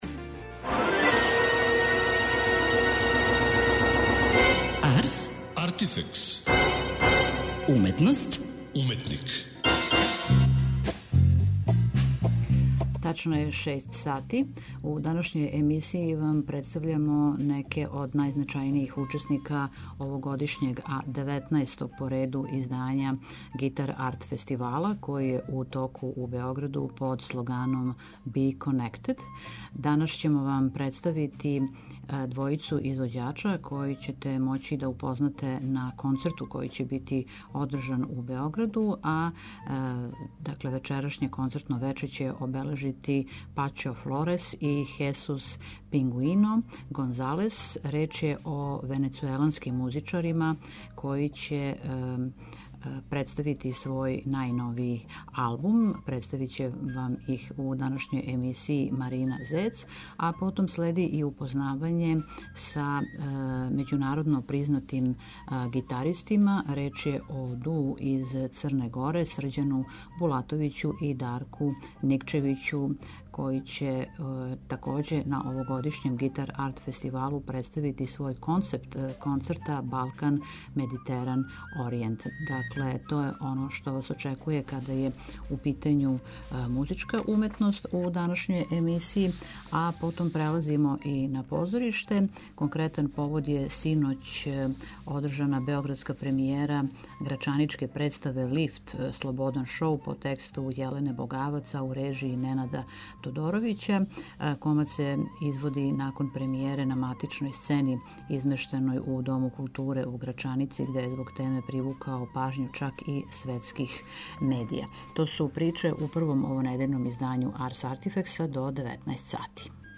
Доносимо разговор са једном од најпознатијих певачица world music сцене, Дулсе Понтеш .